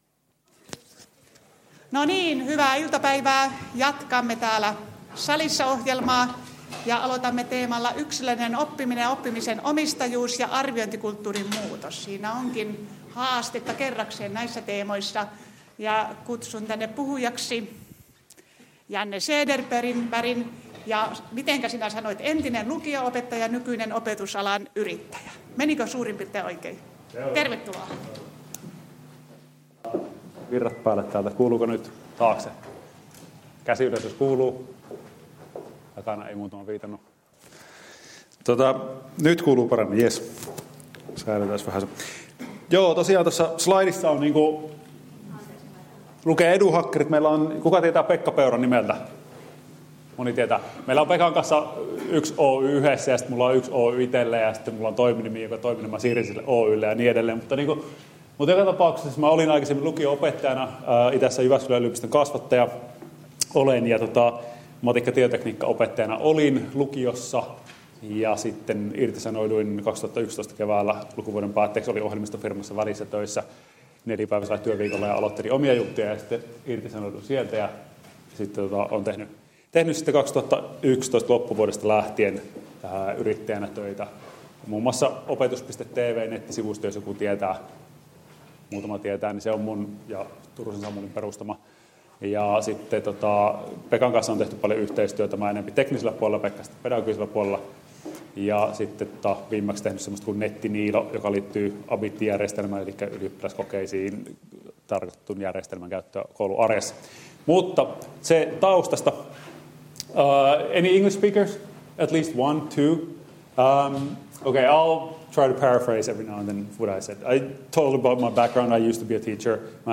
Ohjelma koostuu lyhyistä puheenvuoroista, paneelikeskusteluista, EduFestistä, postereista ja työpajoista.